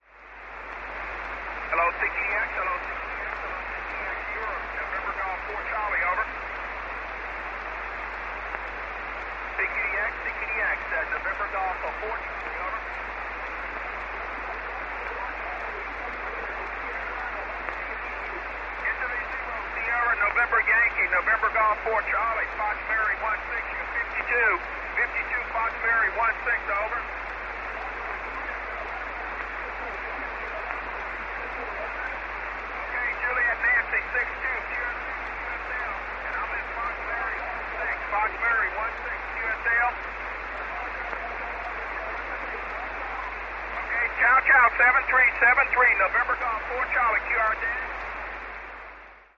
Les USA sur 50 MHz